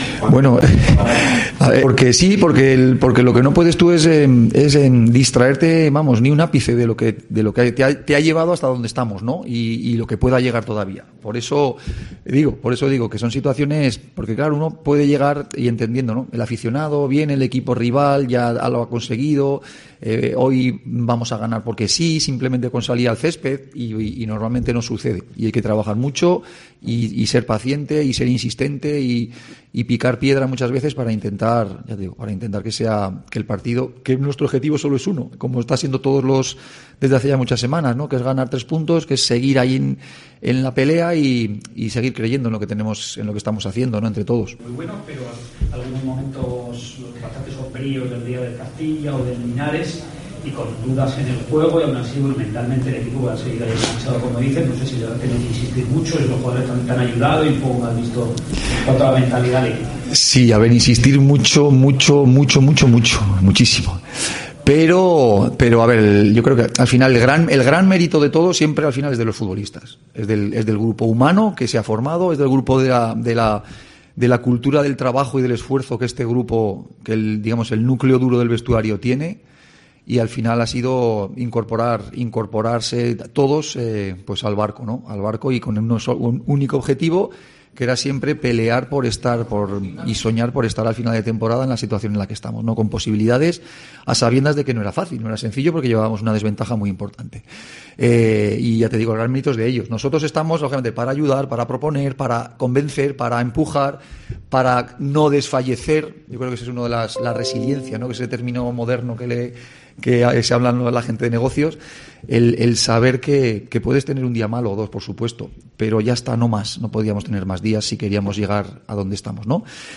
El técnico del Real Murcia analiza el partido de este sábado ante el Mérida en el que asegura que "tenemos que estar concentrados al cien por cien"
El técnico grana, Pablo Alfaro, ha comparecido, antes de comenzar la última sesión de trabajo, para analizar el compromiso, destacando que ·"estos partidos los carga el diablo".